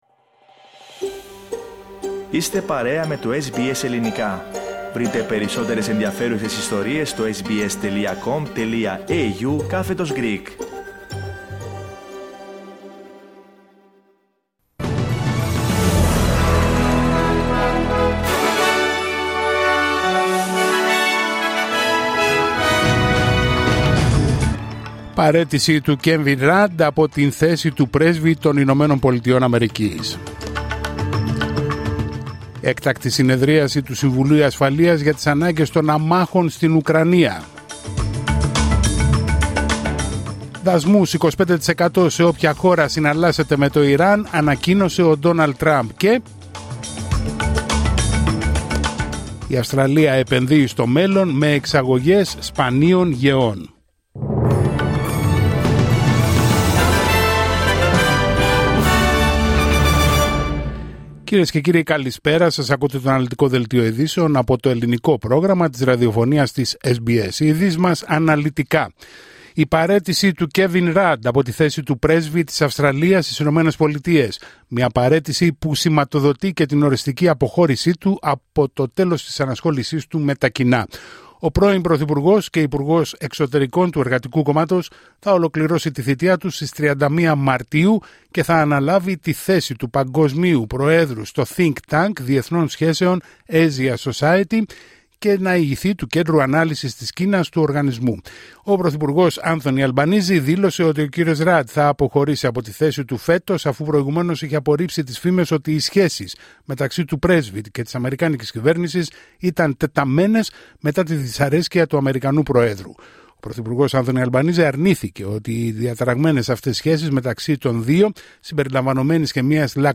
Δελτίο ειδήσεων Τρίτη 13 Ιανουαρίου 2026